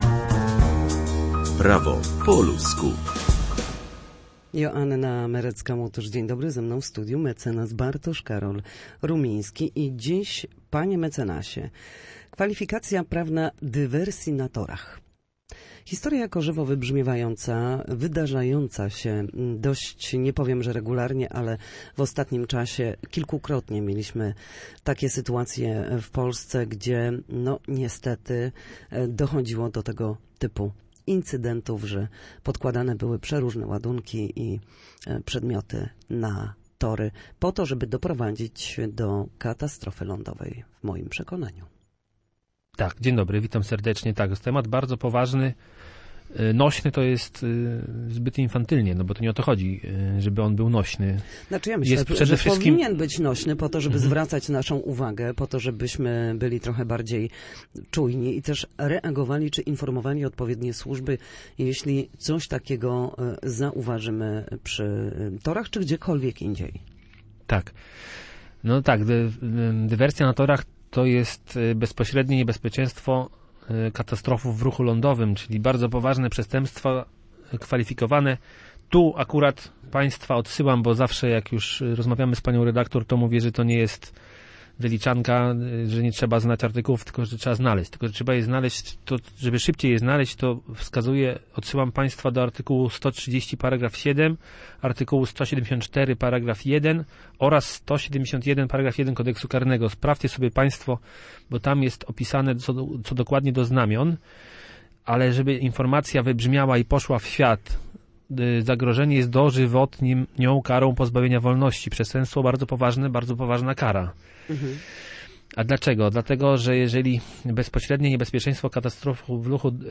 W każdy wtorek o godzinie 13:40 na antenie Studia Słupsk przybliżamy Państwu meandry prawa.